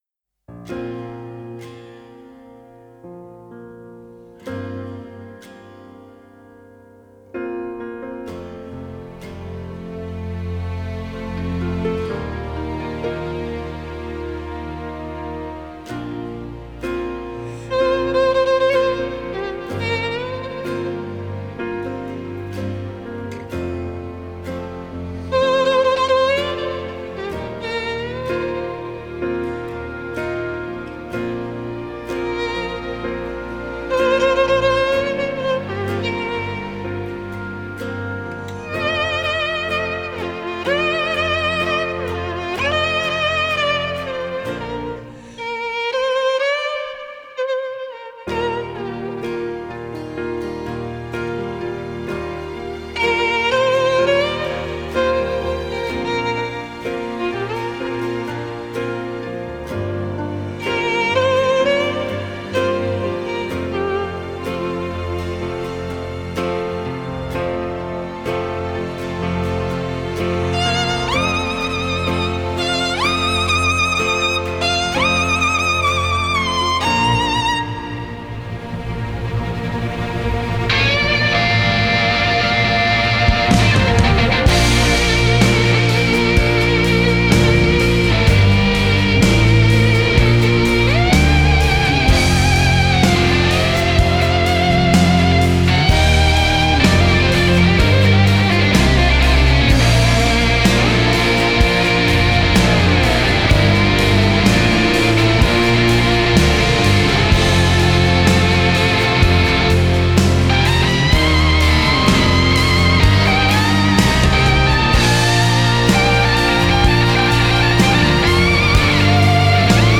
Genre: Pop